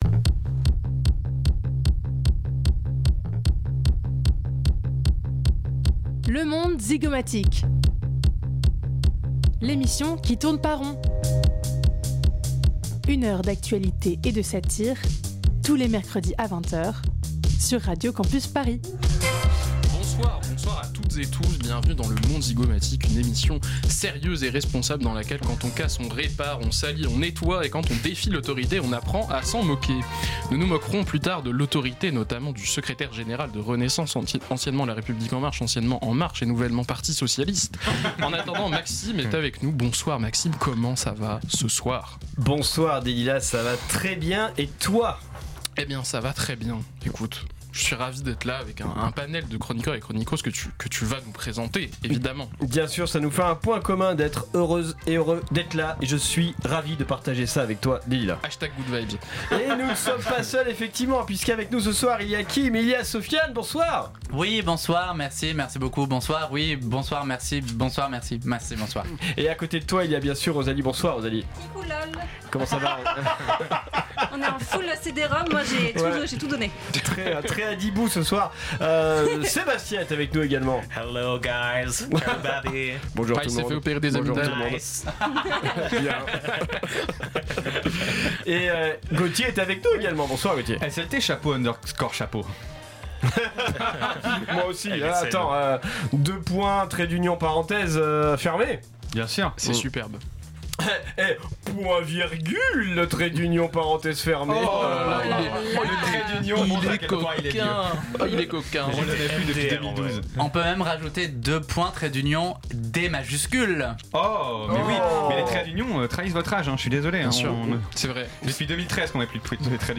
Magazine Société